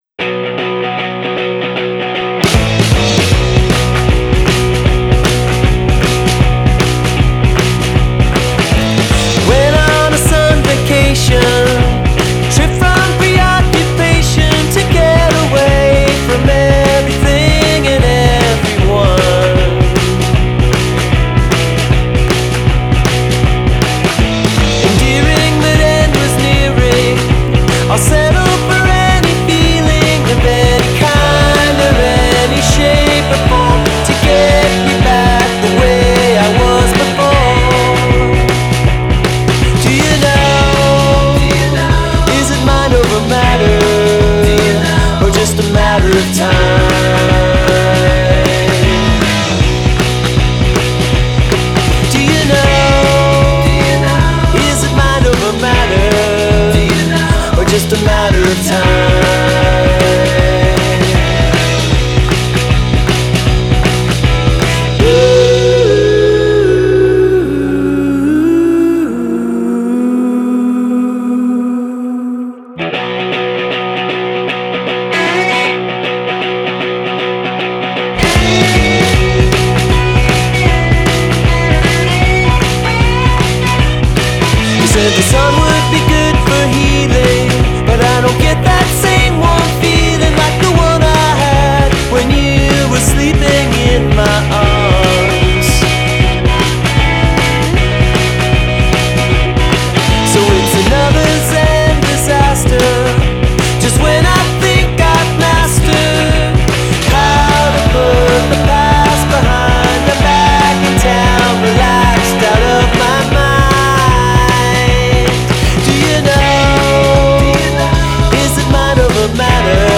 From its opening chugging riff
power pop